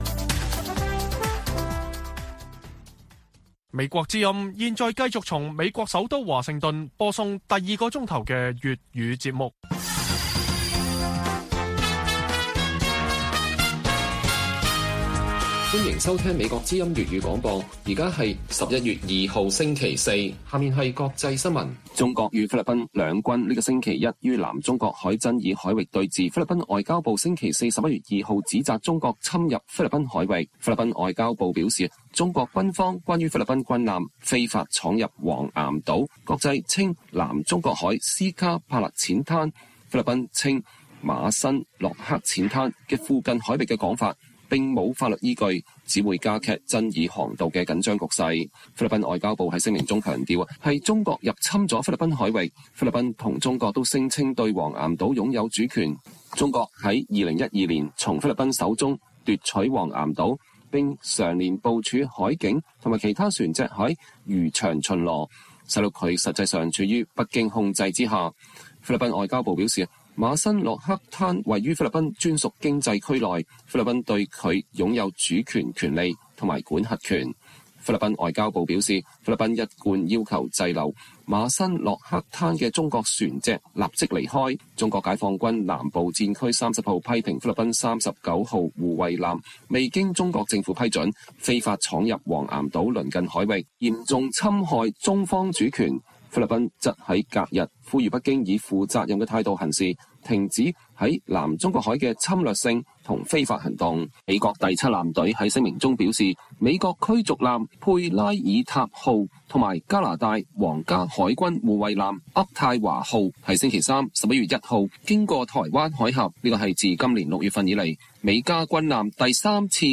粵語新聞 晚上10-11點: 馬尼拉指責中國在南中國海域內“侵入菲律賓海域”